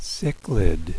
.wav Cichlids ("sick-lids") are, by vertebrate standards, a very large family of freshwater, perchlike fishes with perhaps more than 1,500 species.